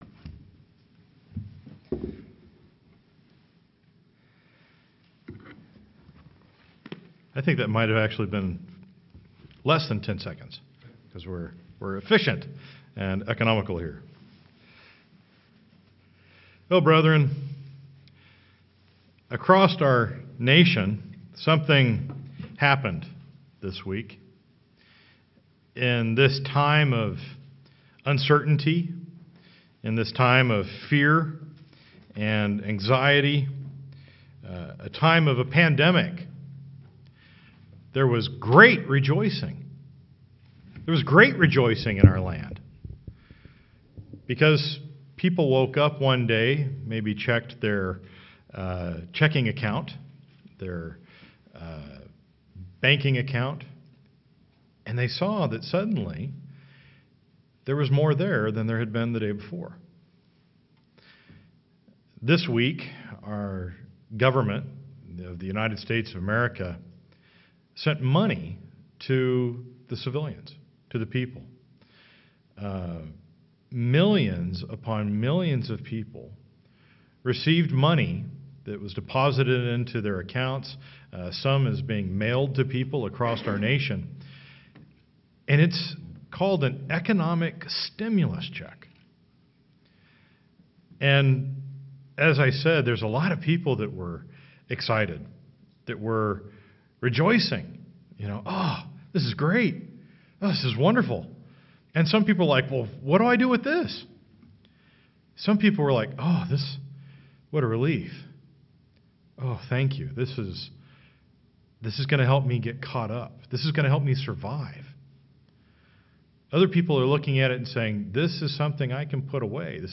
Sermons
Given in Sioux Falls, SD Watertown, SD